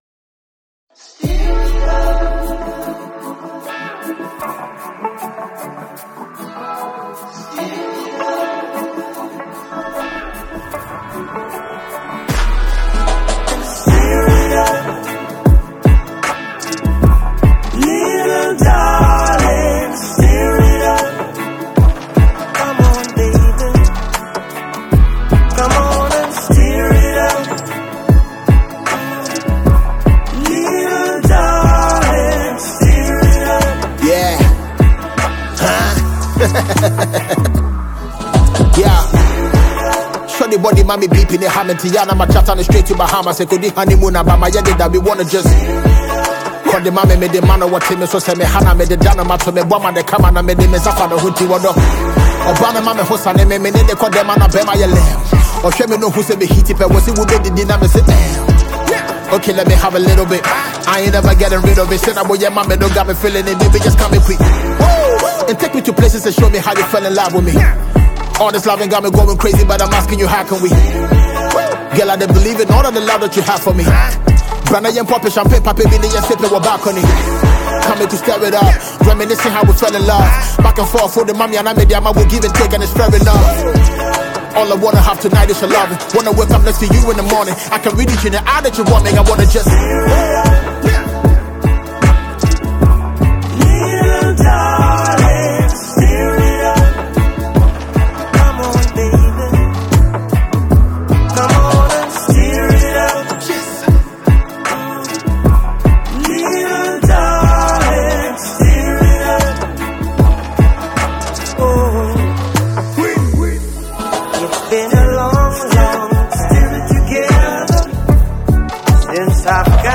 Jamaican Legendary Reggae singer-songwriter